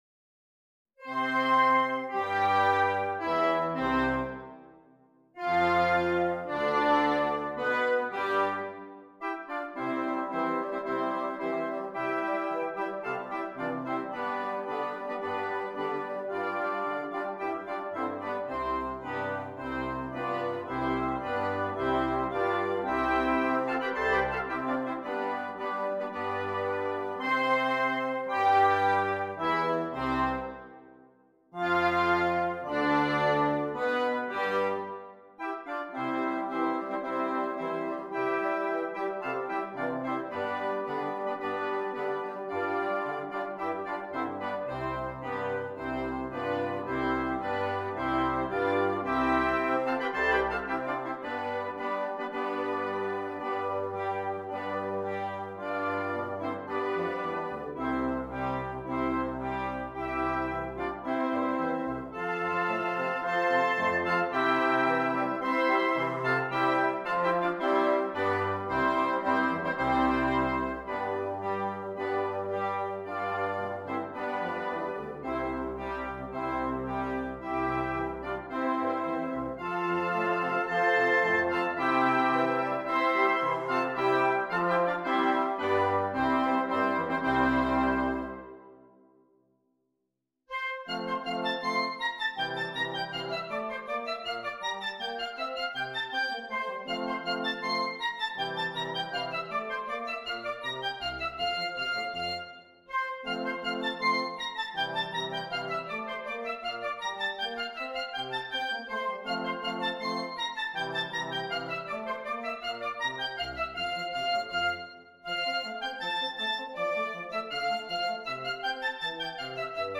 2 Flutes, 2 Oboes, 2 Clarinets,2 Horns, 2 Bassoons